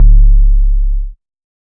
808 (Background).wav